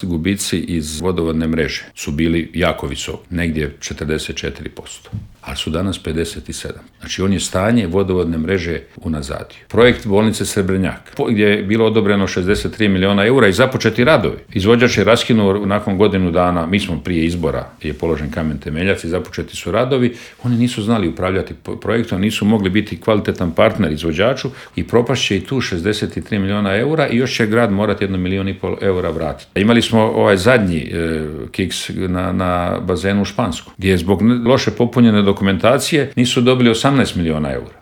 ZAGREB - Predsjednik stranke Plavi Grad, zastupnik u Gradskoj skupštini i kandidat za gradonačelnika Grada Zagreba Ivica Lovrić u Intervjuu Media servisa osvrnuo se na na ključne gradske probleme poput opskrbe plinom, Jakuševca i prometnog kolapsa.